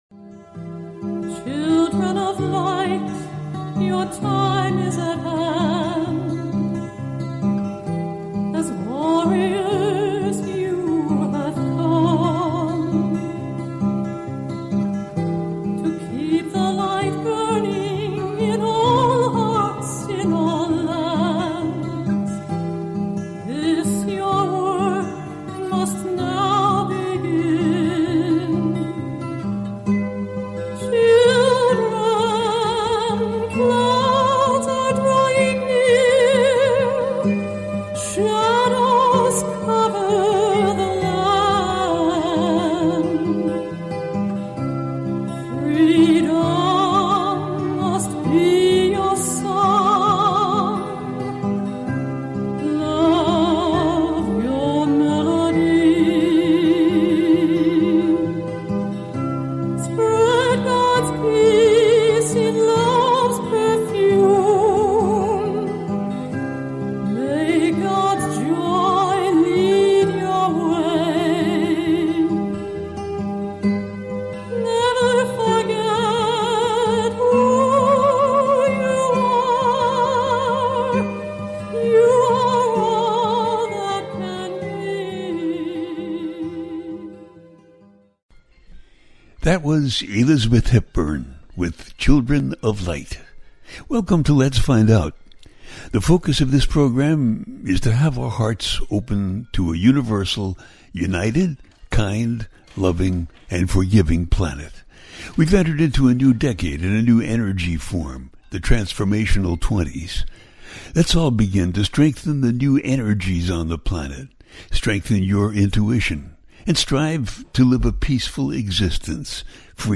First Half of October and the Libra Solar Eclipse - A teaching show
The listener can call in to ask a question on the air.
Each show ends with a guided meditation.